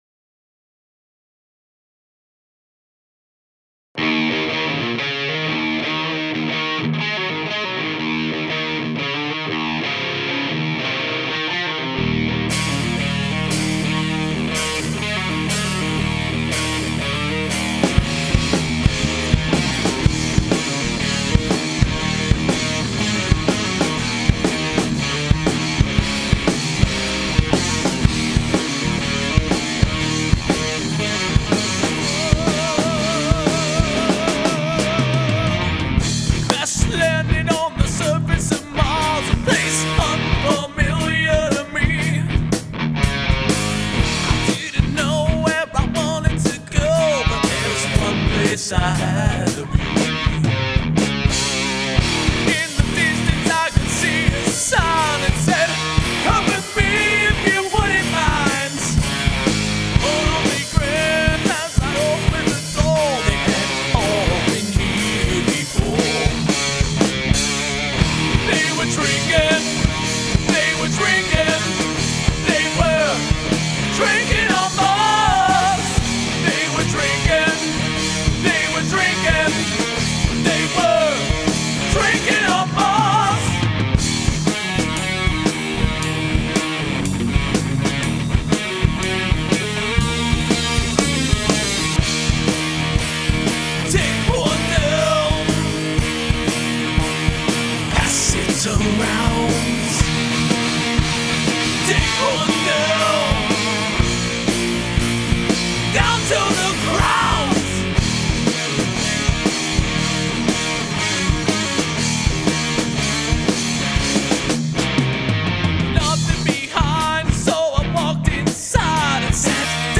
Here's Drinkin' on Mars. I'm having some trouble getting the vocals to sit right in the mix, the singer has a very dynamic range and this was my first experiment with parallel compression...I'm not sure if I'm doing more damage than good at this point. You'll notice some words have far less power than others and can tend to get buried in the mix. The drums could likely use some work too.